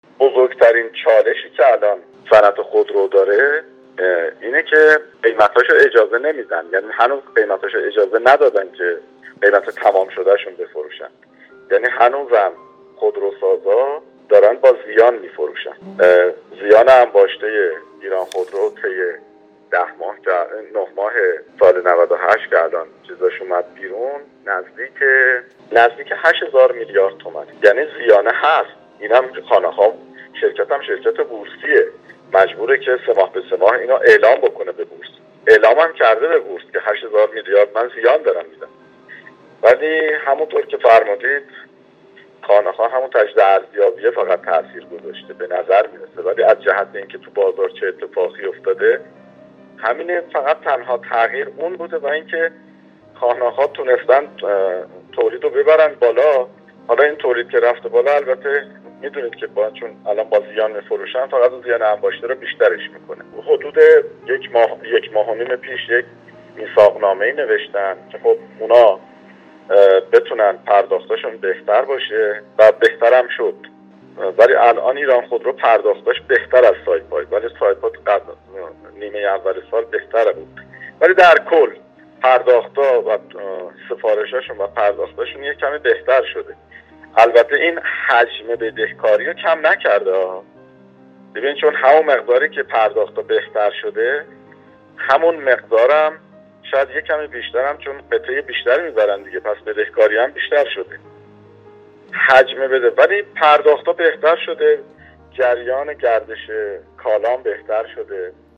در گفت‌وگو با بورس‌نیوز